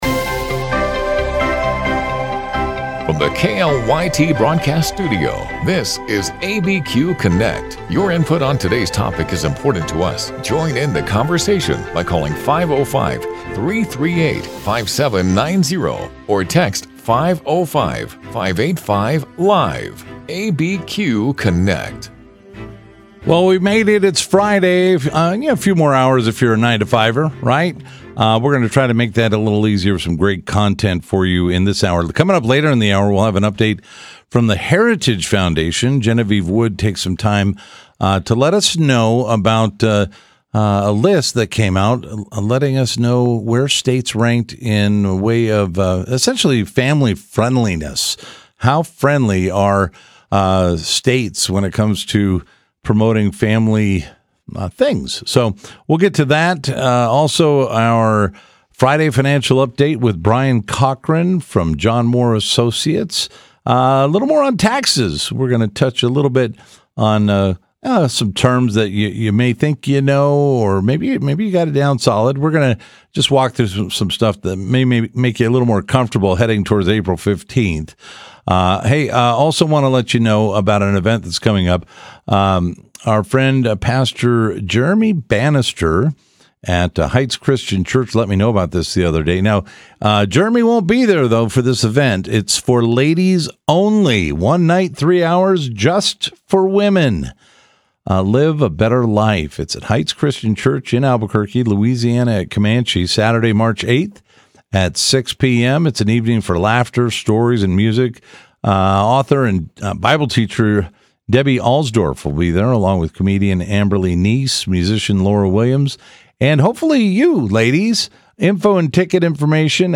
Live, local and focused on issues that affect those in the New Mexico area.
Today we are joined in conversation by volunteers with Calvary Church’s Indigenous Outreach Ministry. They join us today to talk about an upcoming interest meeting for this ministry and how they each became involved in ministry to indigenous people living in Arizona and New Mexico.